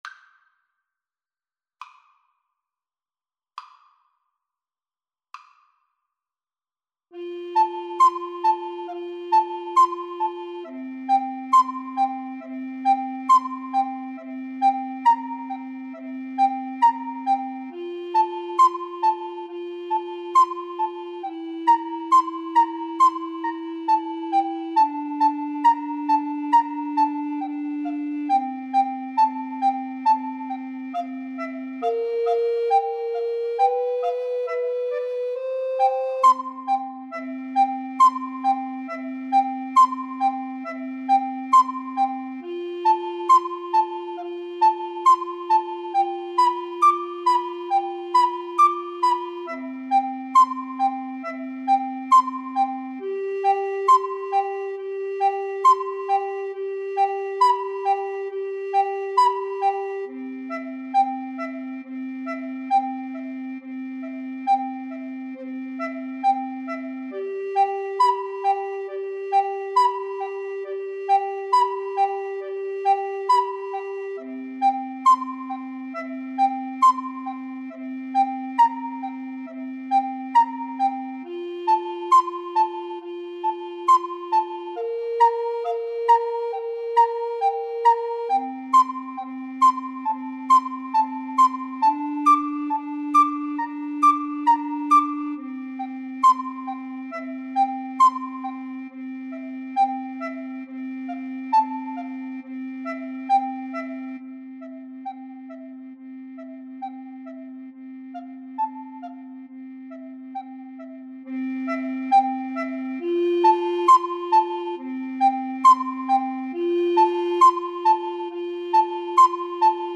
Soprano RecorderSoprano RecorderTenor Recorder
4/4 (View more 4/4 Music)
= 34 Grave
Recorder Trio  (View more Intermediate Recorder Trio Music)
Classical (View more Classical Recorder Trio Music)